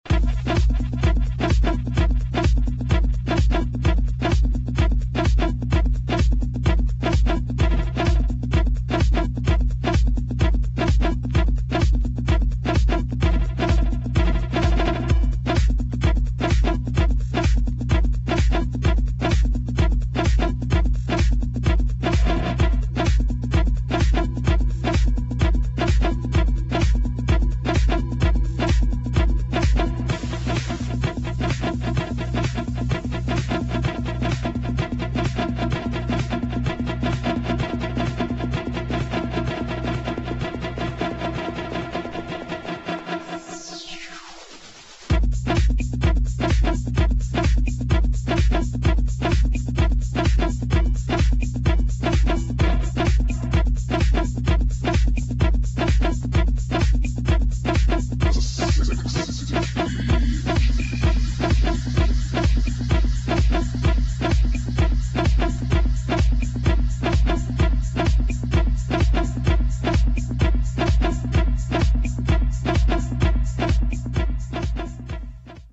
[ TECH HOUSE | ELECTRO ]